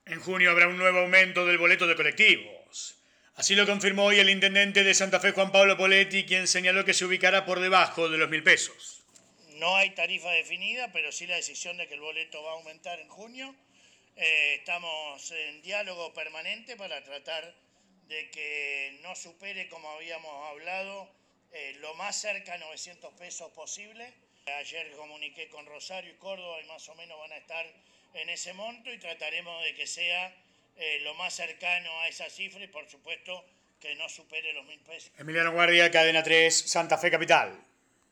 El intendente de Santa Fe, Juan Pablo Poletti, confirmó en diálogo con Cadena 3 que el precio del boleto de colectivo sufrirá un nuevo aumento en junio.
Informe